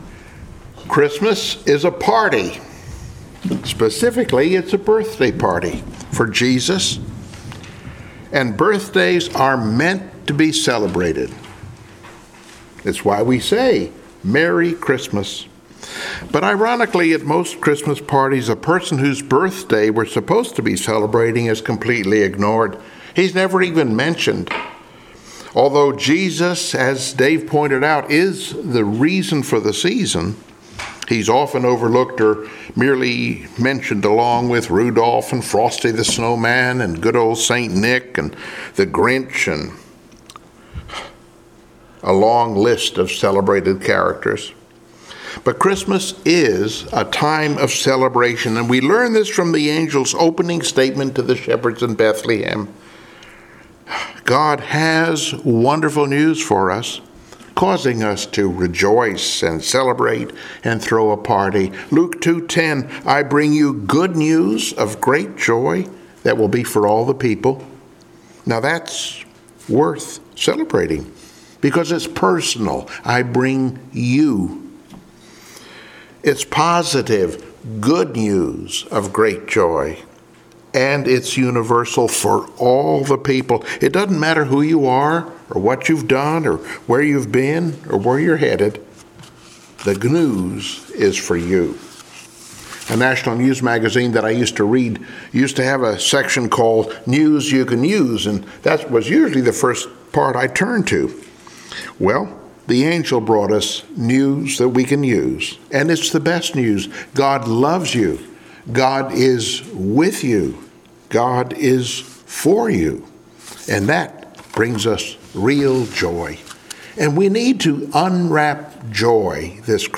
Passage: Luke 1:14 Service Type: Sunday Morning Worship Download Files Bulletin Topics